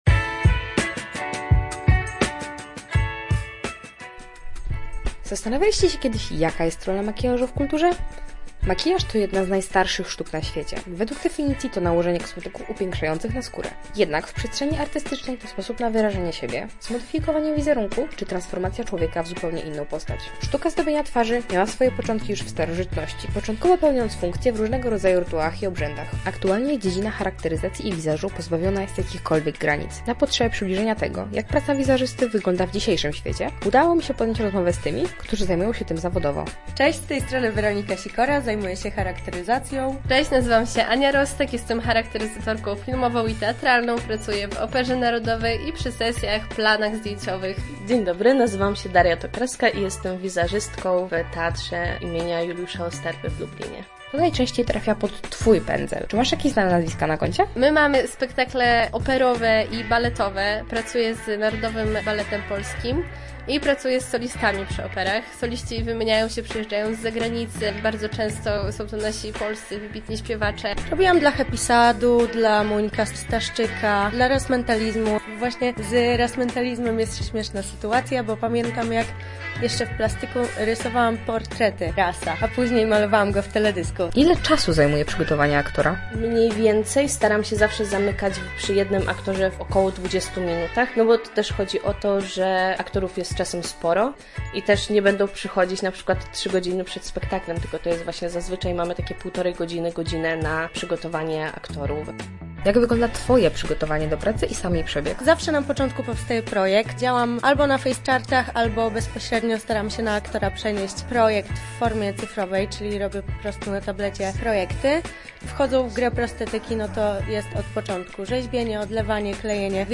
rozmawiała ze specjalistami w dziedzinie makijażu scenicznego, którzy od lat oddają się temu zajęciu.